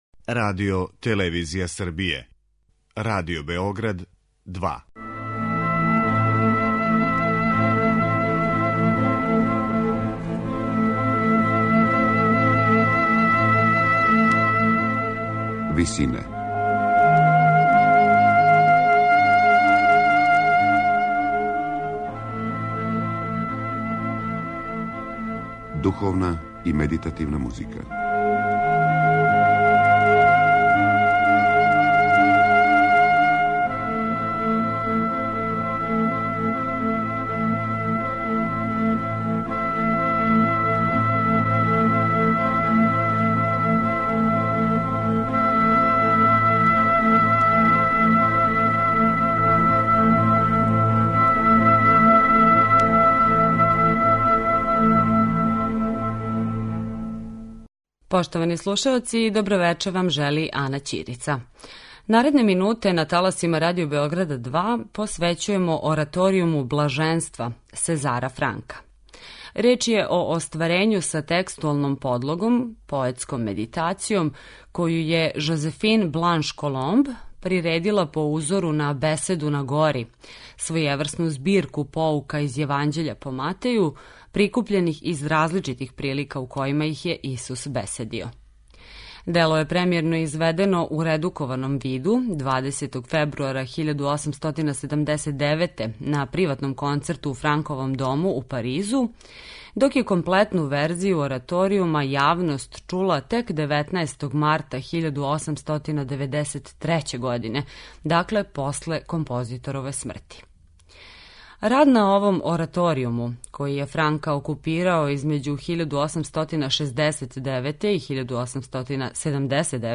Сезар Франк: ораторијум „Блаженства'